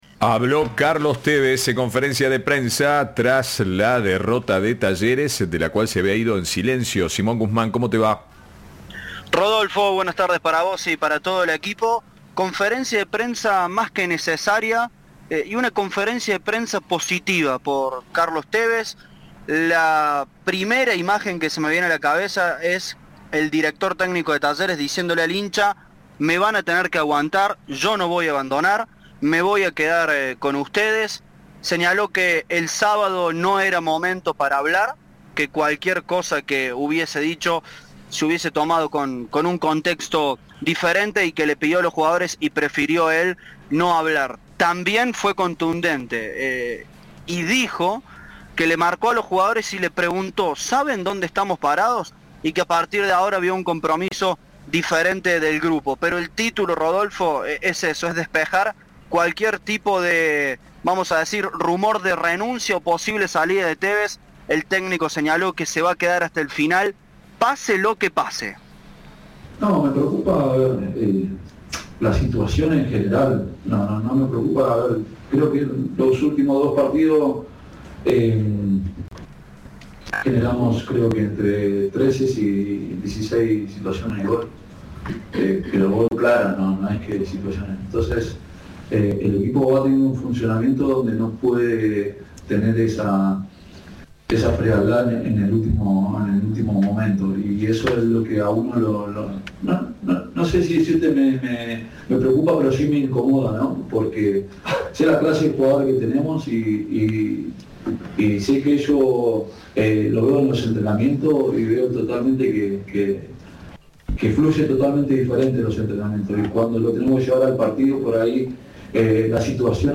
El director técnico habló en conferencia de prensa y aseguró que seguirá en "el Matador".
Carlos Tévez habló en conferencia de prensa tras la dura derrota de Talleres ante Atlético Tucumán, de la cual se fue en silencio.